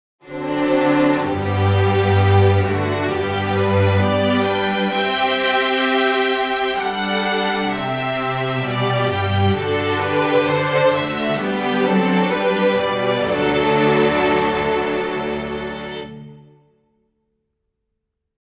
Получил тут на днях порцию неудовольствия от одного знакомого аранжировщика )) по поводу ансамблевого состава струнных, типа камерного, о том что его чертовски сложно сделать, и решил таки поковырять это дело. Набрал состав строго из сольных библ, кроме спитовских чамберов - их для объёмчика добавил немного.
Запись только легато и под метроном.